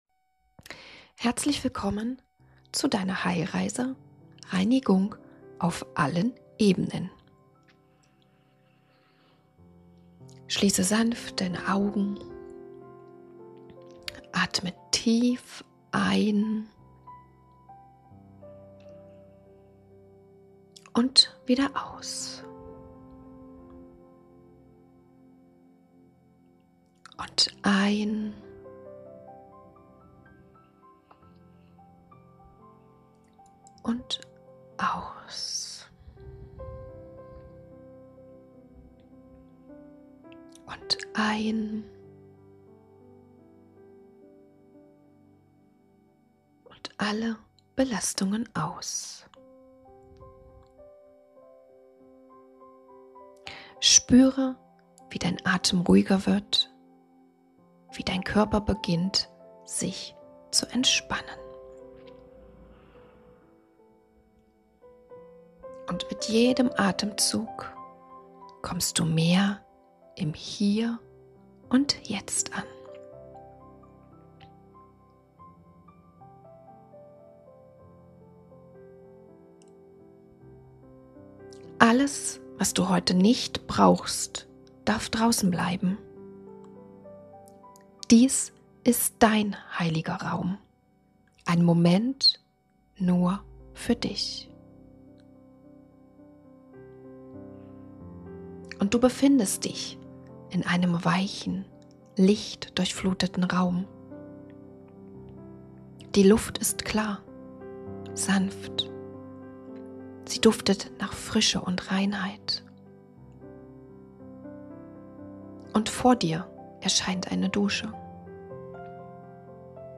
Sanft wirst du in den Moment geführt. Du schließt deine Augen, atmest zur Ruhe und lässt dich Schritt für Schritt durch eine tiefe Reinigung begleiten – körperlich, emotional, mental und seelisch.